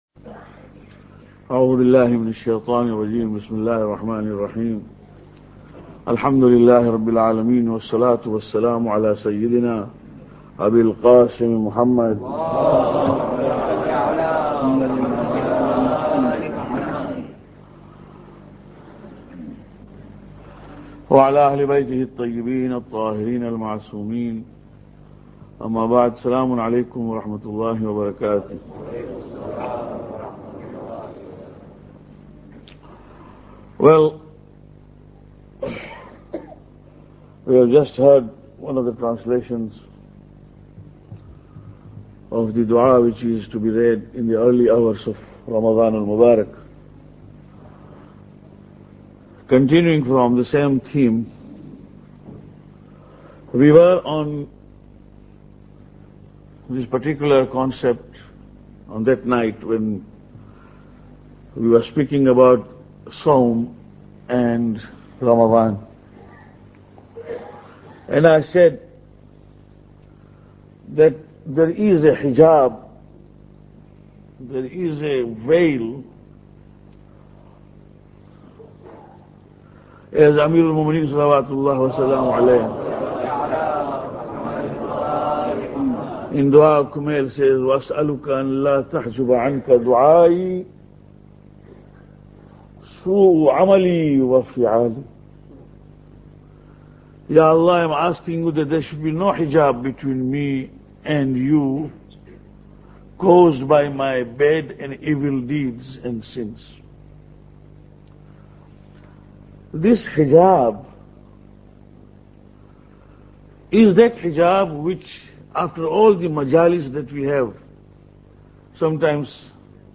Lecture 13